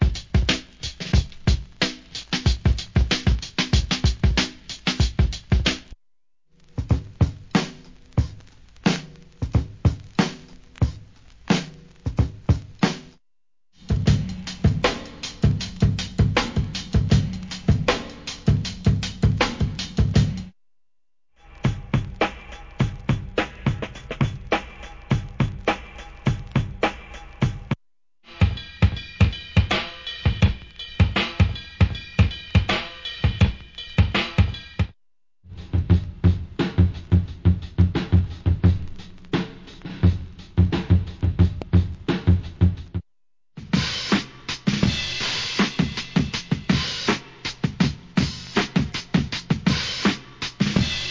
HIP HOP/R&B
トラック・メイクにはもってこいのシンプルなBEATS集、第一弾!!